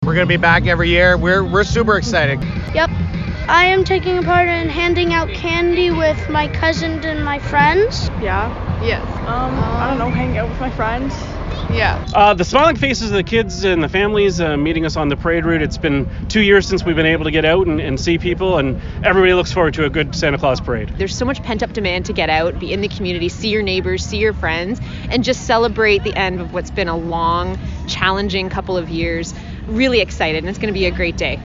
Over 60 floats rolled down Milton’s Main Street with thousands lining the sidewalks to get a glimpse at the fun. Participants spoke with us about what they were excited for the most.